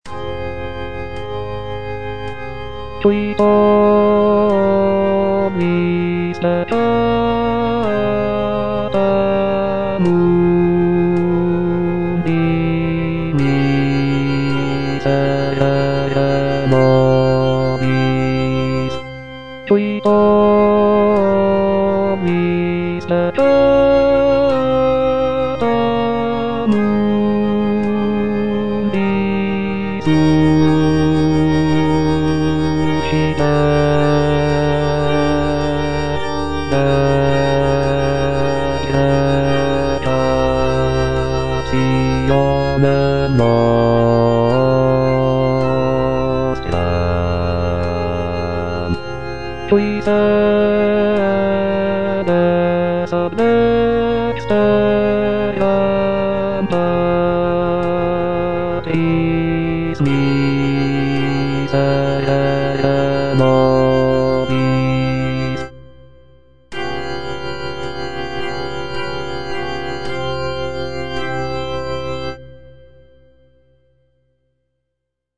T. DUBOIS - MESSE IN F Qui tollis - Bass (Voice with metronome) Ads stop: auto-stop
"Messe in F" is a choral work composed by Théodore Dubois in the late 19th century. It is a setting of the traditional Catholic Mass text in the key of F major.